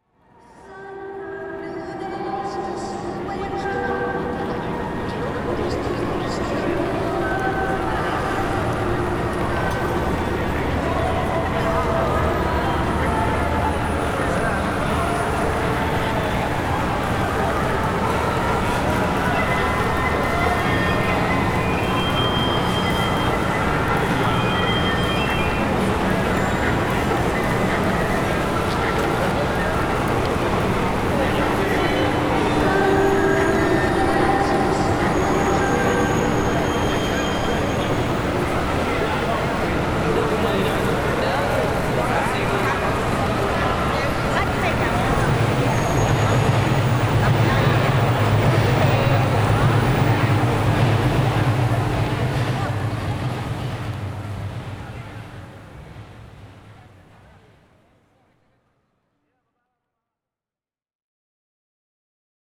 Et oui, vous l’avez compris avec le dernier extrait, on n’est plus réellement dans l’ambiance mais plus dans le bruitage J’ai repéré dans mes fichiers plusieurs autres sons que je voulais voir apparaitre : une annonce sonore à Shibuya qui va donner un coté musical à l’ensemble, des gongs dans un temple, un métro qui passe, un néon qui crépite… bref tout ce qu’il faut pour donner une vie à notre ville.
J’ajoute un delay sur l’annonce sonore pour donner un coté Blade Runner, en faisant ça je l’ai trouvée très musicale et j’ai donc recollé l’extrait quelques mesures plus loin en le calant sur le tempo.
Chaque bruitage est ensuite placé dans la panoramique.
Puis j’ajoute une automatisation pour en déplacer certains pendant l’écoute et donner du mouvement à notre ville.
07-Bruitages-vrais.wav